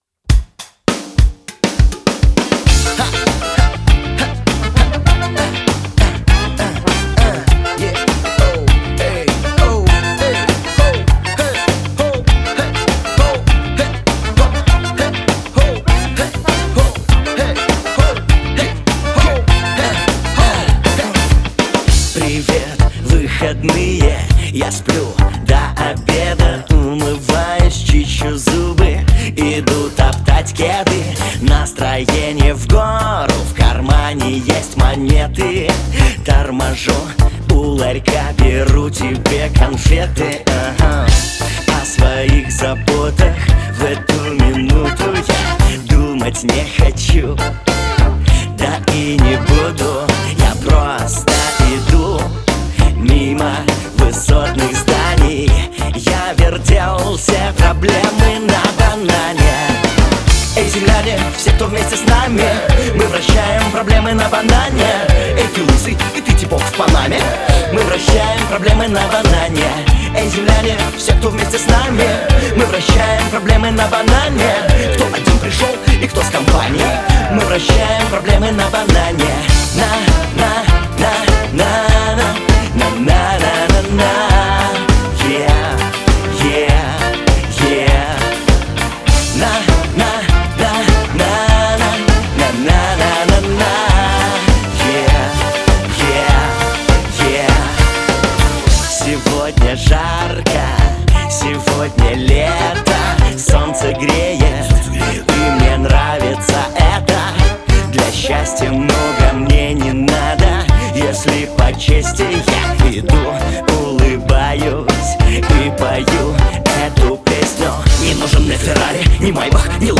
несерьезная около регги песенка, и тут все в порядке с грувом ) живье